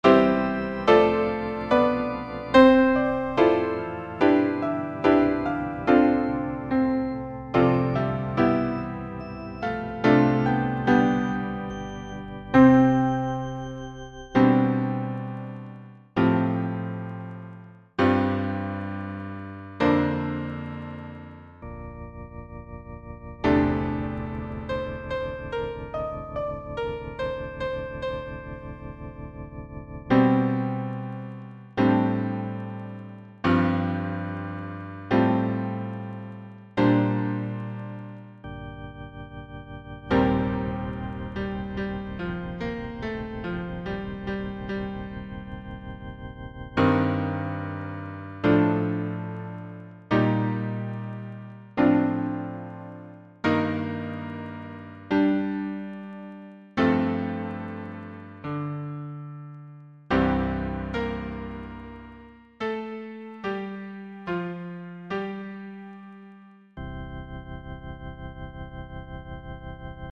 Chanté:     S   A   T   B1   B2